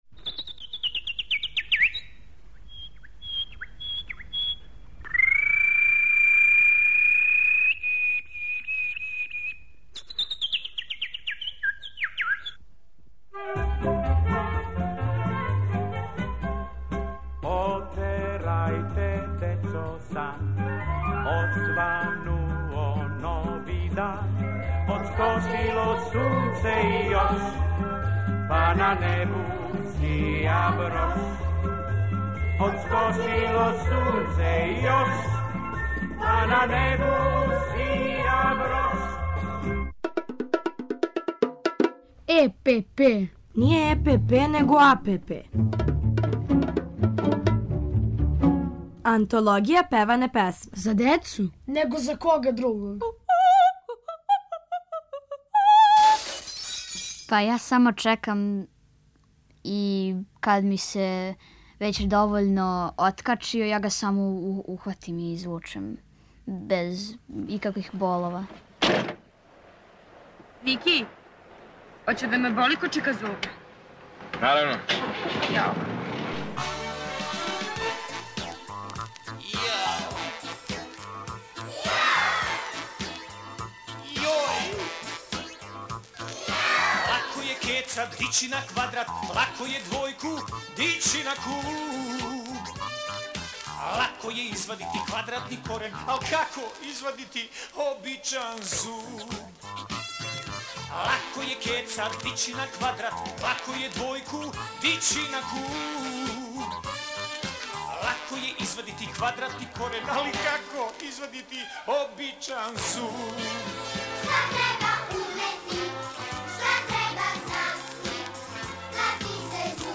У серијалу АНТОЛОГИЈА ПЕВАНЕ ПЕСМЕ певамо и причамо о томе како извадити зуб.